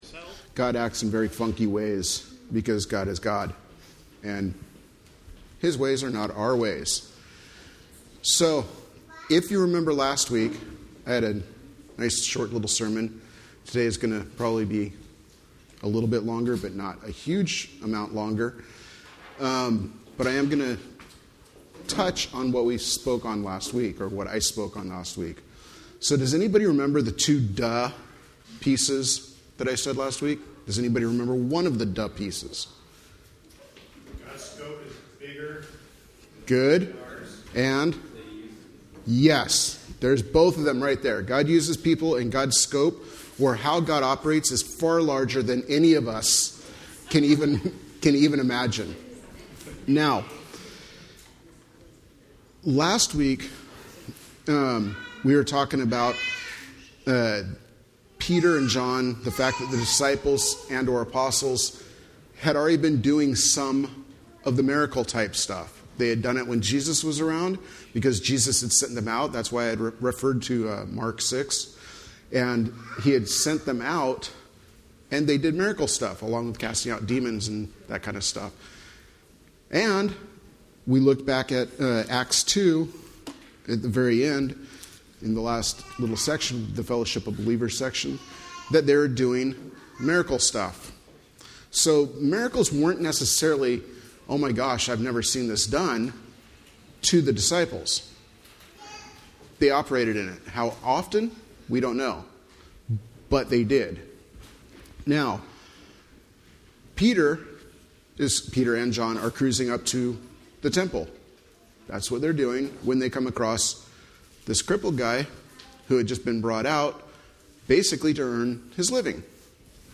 Acts 3:11-26 Service Type: Sunday Morning Related « Getting our Acts Together